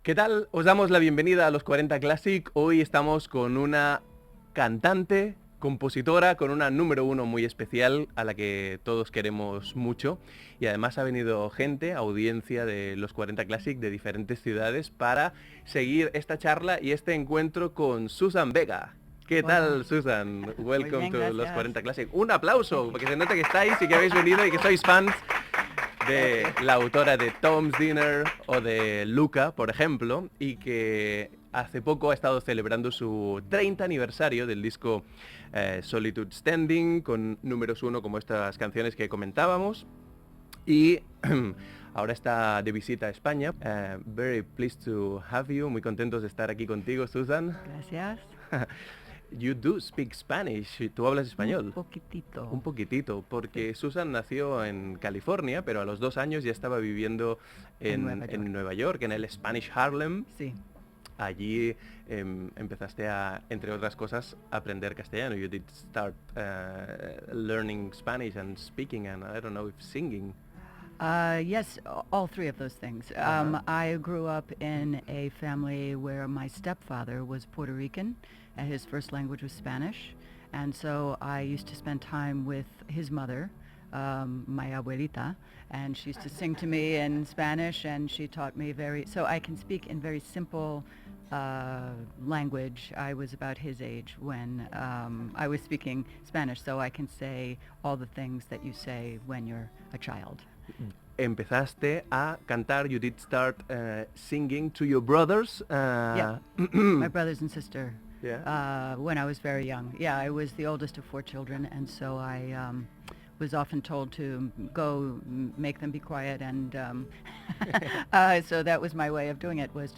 Entrevista a la cantant Suzanne Vega. S'hi parla de la seva infància i joventut, de la mica de castellà que sap, del nom Luka, de la seva gira musical